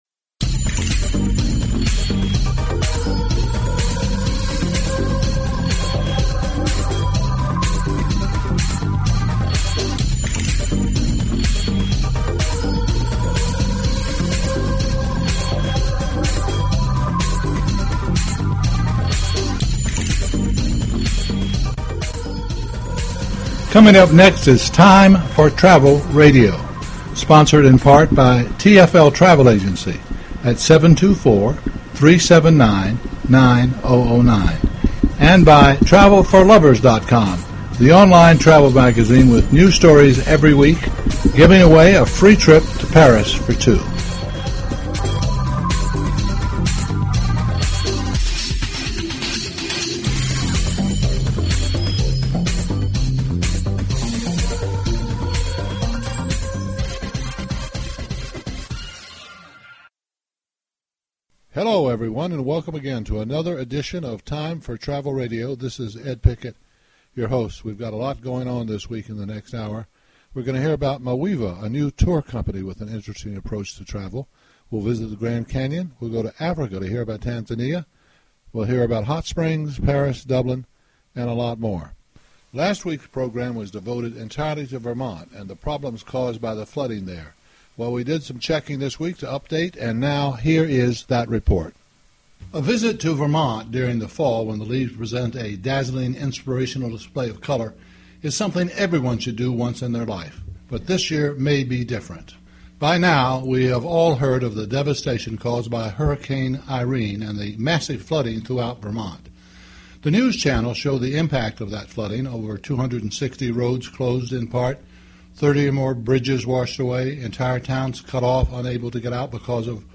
Talk Show Episode, Audio Podcast, Time_for_Travel_Radio and Courtesy of BBS Radio on , show guests , about , categorized as